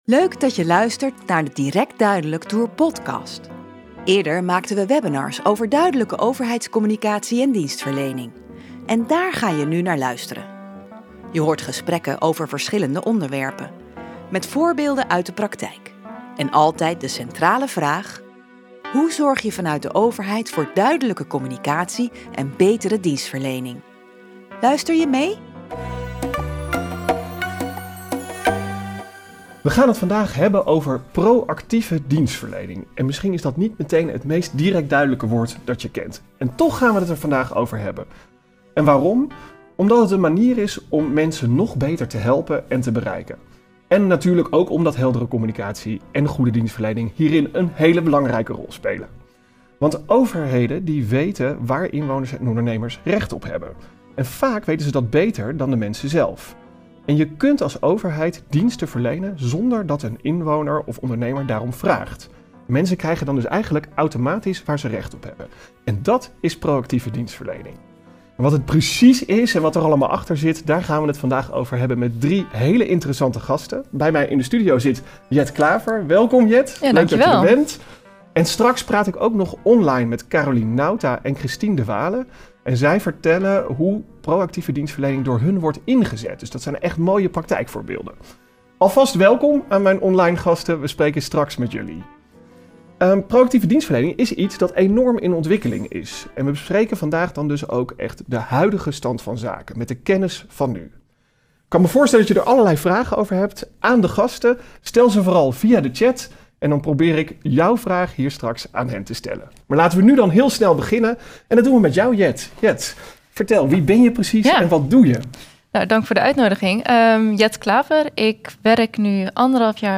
Luister het webinar van de Direct Duidelijk Tour terug in deze podcast. Dit keer over proactieve dienstverlening.
Je hoort gesprekken over verschillende onderwerpen.